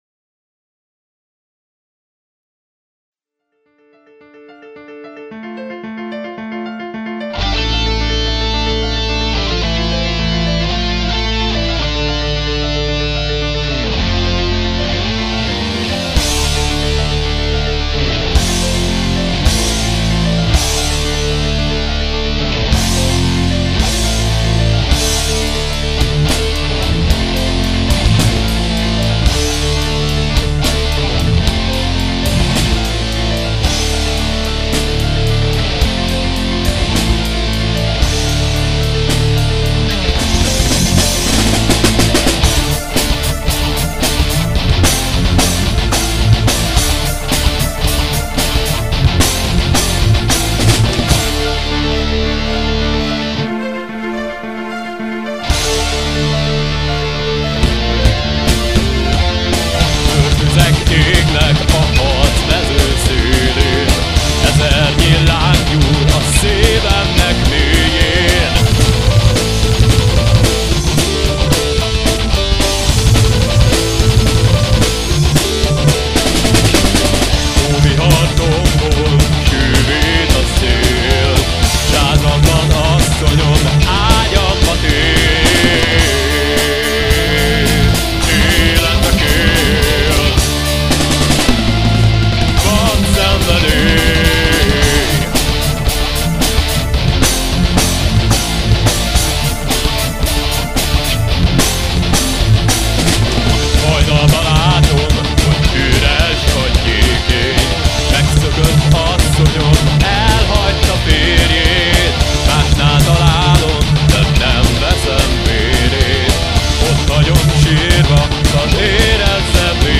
szólógitár
billentyű
basszusgitár